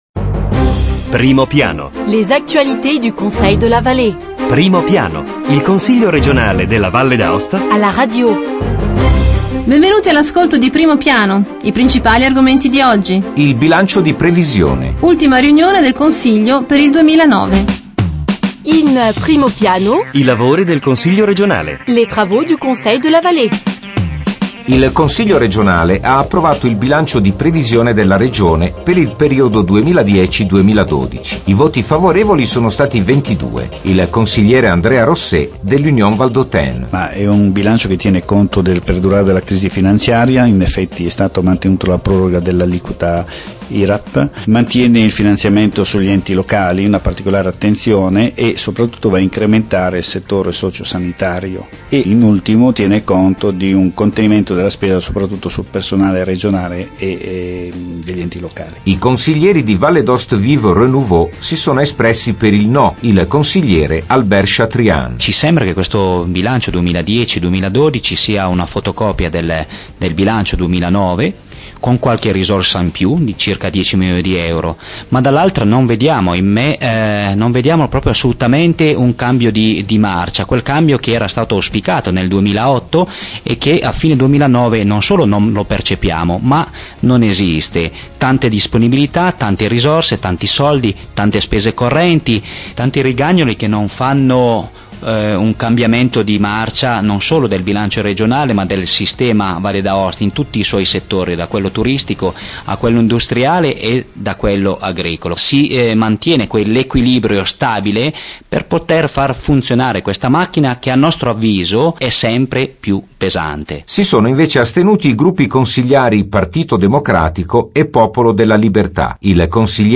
Voici les th�mes de la nouvelle transmission: Les travaux du Conseil de la Vall�e: la loi du budget et la loi des finances pour les ann�es 2010-2012 ont �t� approuv�es. Interviews aux Conseillers Andrea Rosset (UV), Albert Chatrian (VdA Vive/R) et Massimo Lattanzi (PdL).